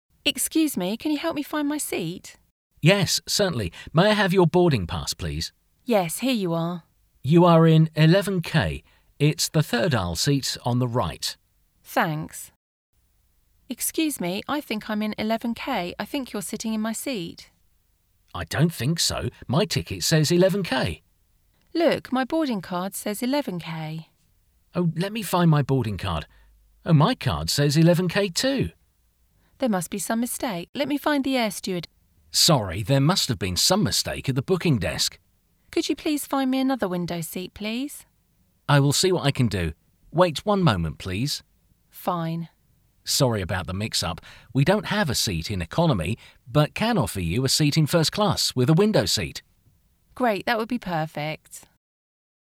Ebben a rövid párbeszédben egy repülőn játszódó párbeszédet olvashatsz el és hallgathatsz meg.